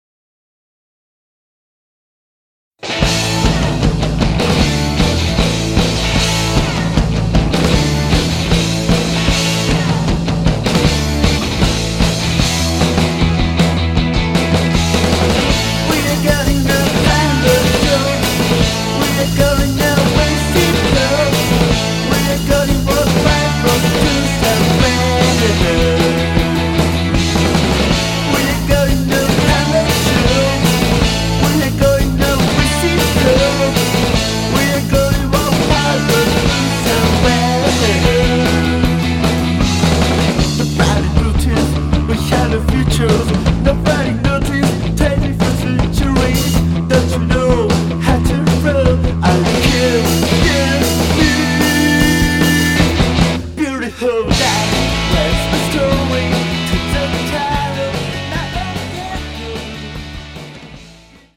Power Pop